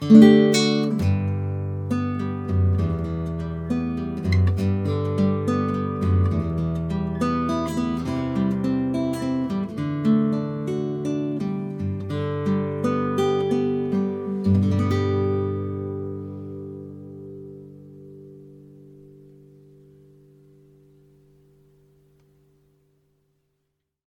Crossover Nylon Strings
Nice looking and sweet sounding guitar!